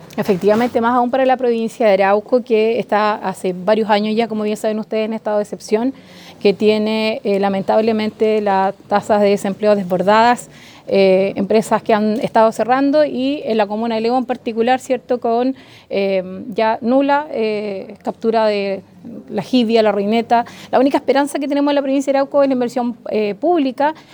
alcaldesa-lebu.mp3